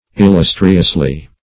Illustriously \Il*lus"tri*ous*ly\, adv.